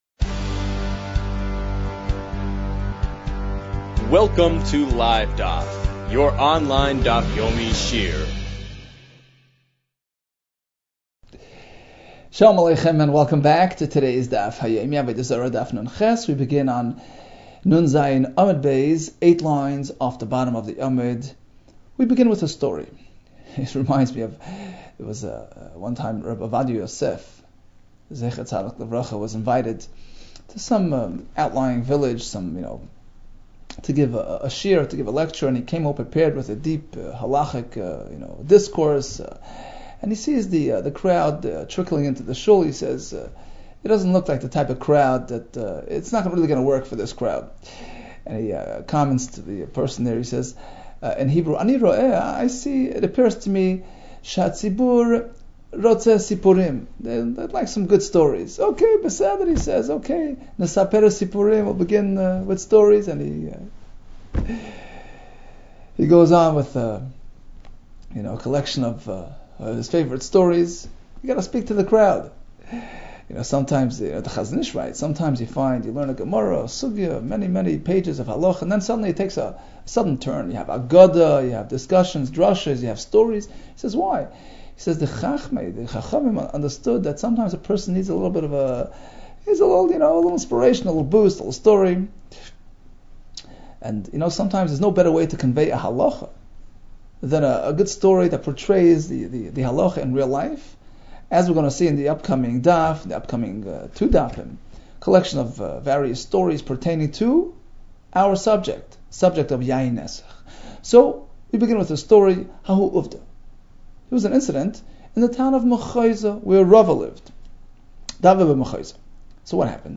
Avodah Zarah 57 - עבודה זרה נז | Daf Yomi Online Shiur | Livedaf